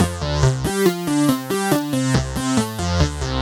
Index of /musicradar/french-house-chillout-samples/140bpm/Instruments
FHC_Arp C_140-C.wav